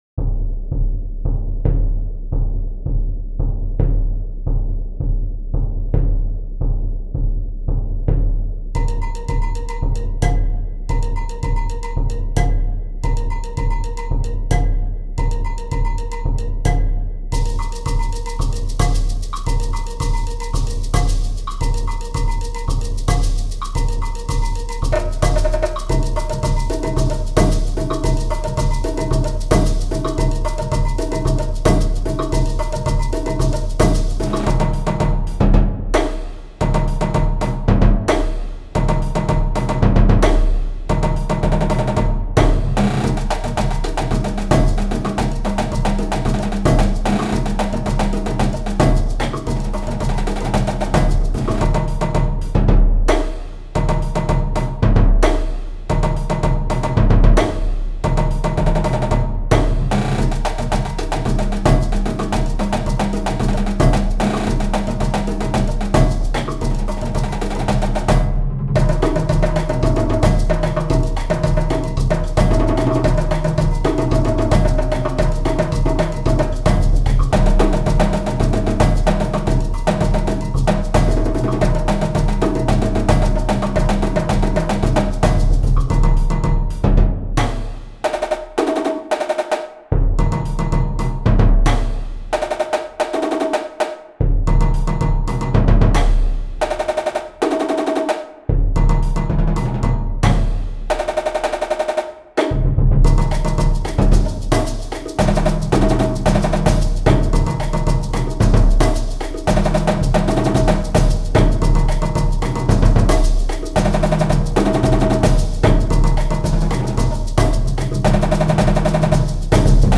Genre Jazz & Latin